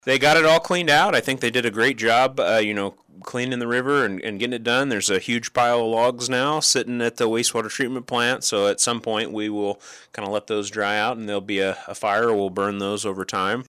Salina City Manager Jacob Wood tells KSAL News that Diehl Enterprises got the job done.